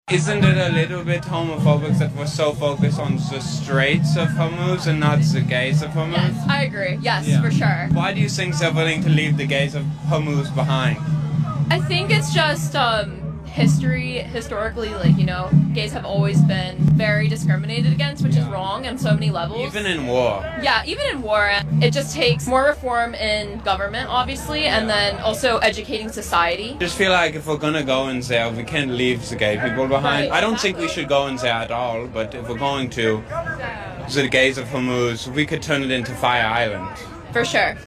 No Kings Protest: